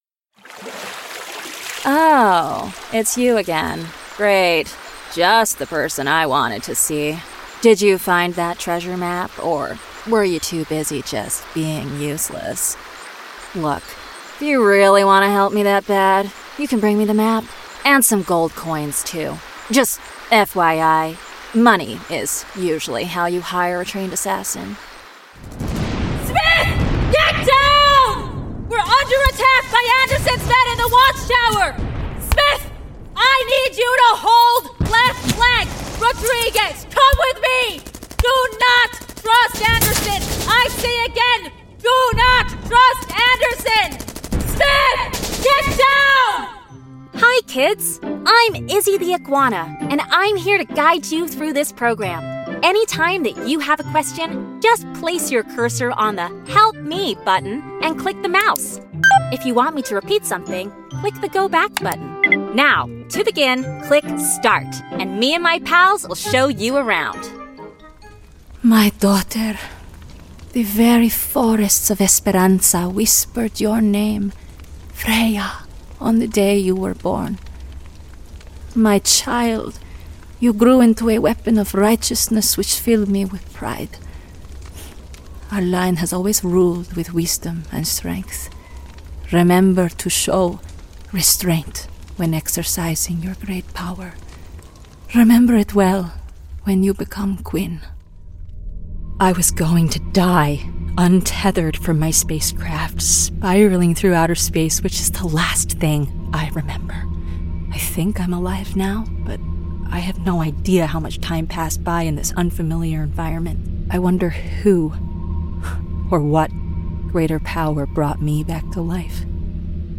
Playing age: Teens - 20s, 20 - 30s, 30 - 40sNative Accent: American, RPOther Accents: American, Australian, Estuary, International, London, RP, Yorkshire
• Native Accent: American Standard, RP
• Home Studio